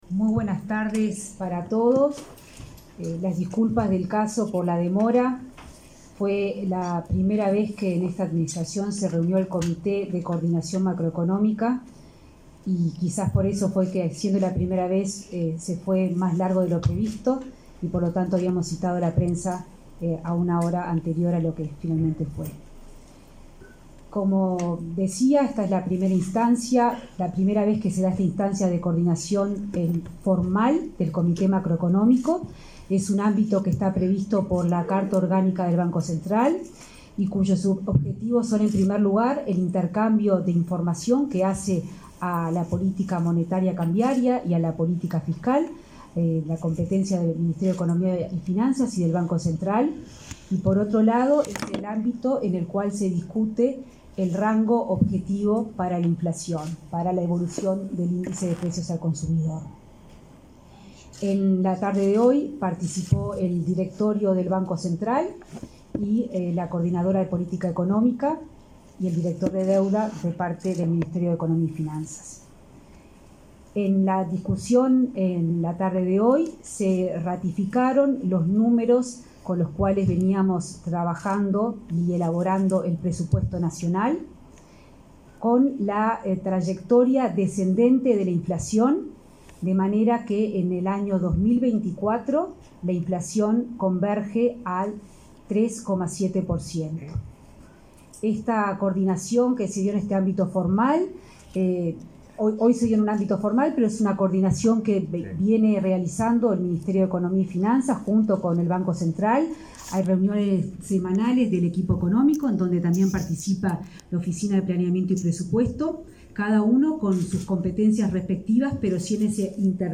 Por otra parte, se desplegará una serie de medidas para desdolarizar la economía nacional, adelantaron la ministra Azucena Arbeleche y el presidente del Banco Central, Diego Labat.
conferencia.mp3